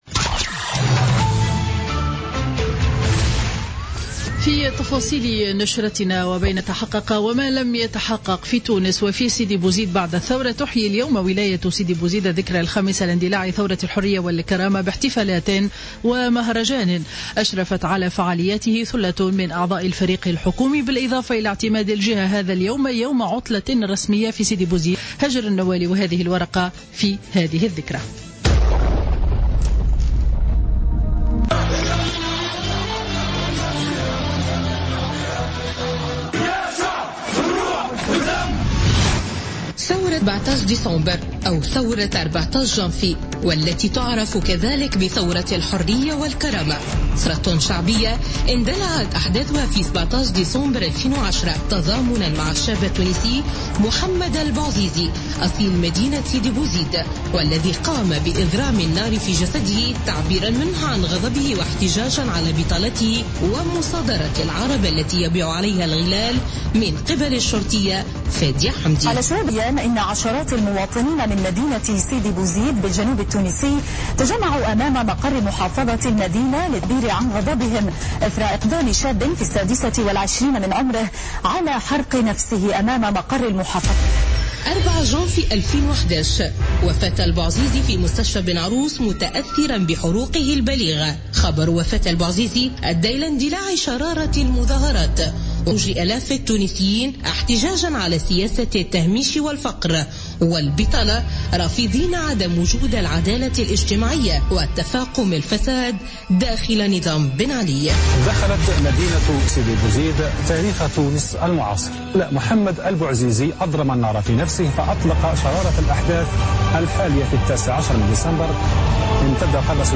نشرة أخبار منتصف النهار ليوم الخميس 17 ديسمبر 2015